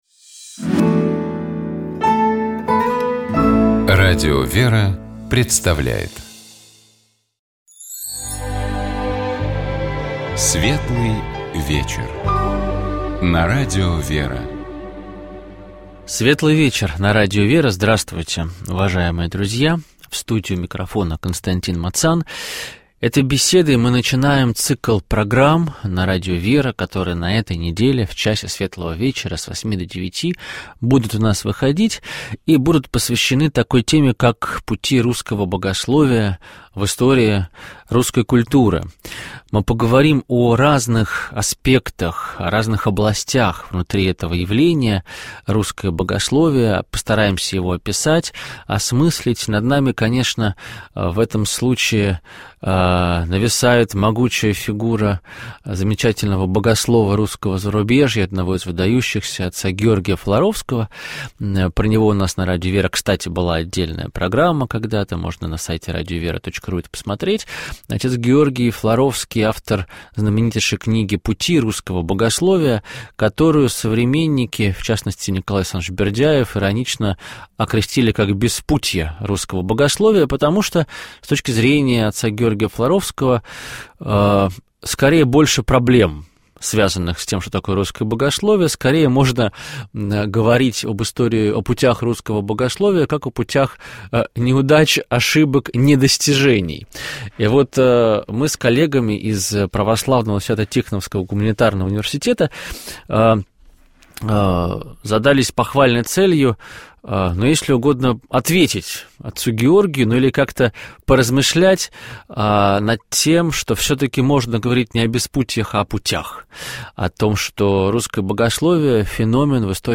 В этом выпуске ведущие Радио ВЕРА